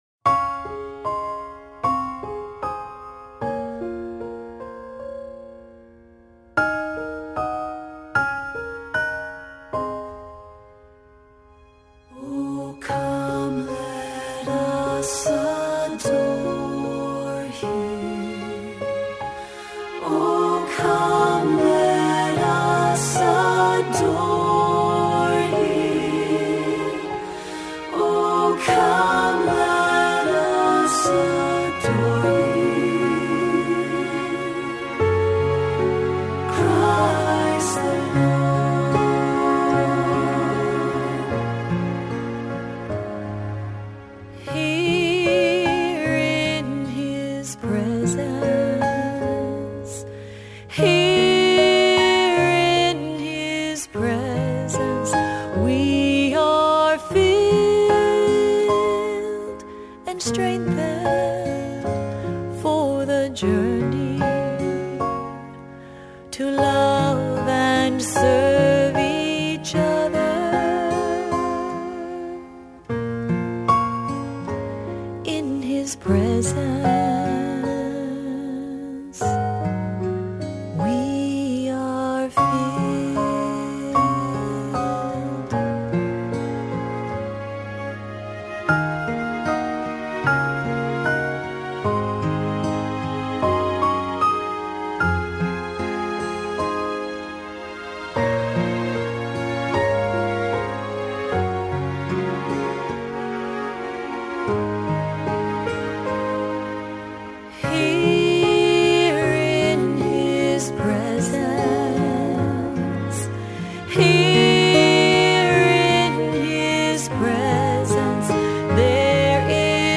Voicing: SAB; solo